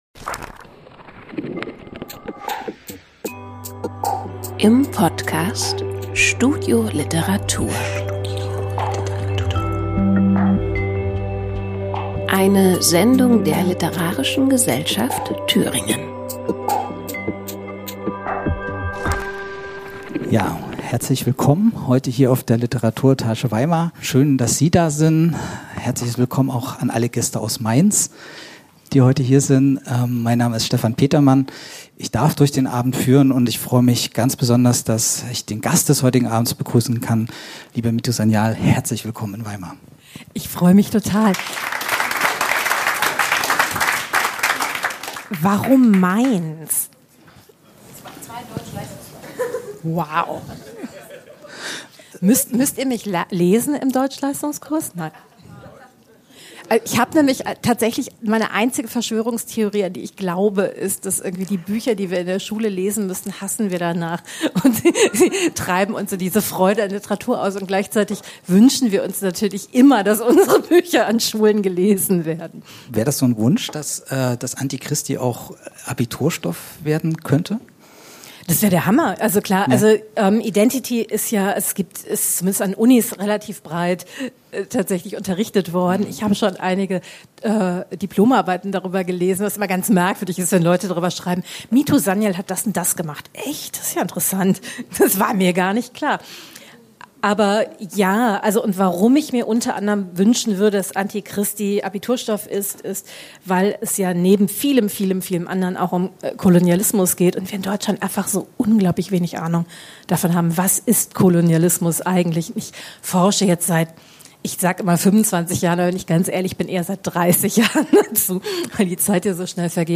Aufzeichnung einer Lesung in der Weimarer LiteraturEtage vom 16.01.2026